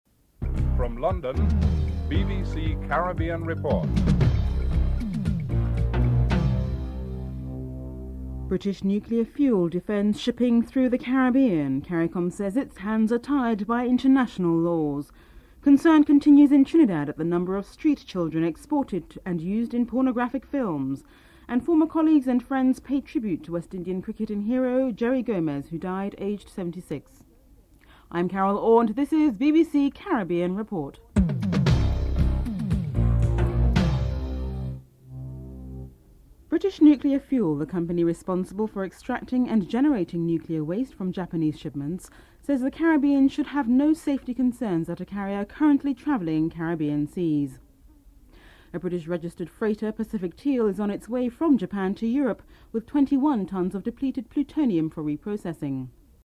1. Headlines (00:00-00:35)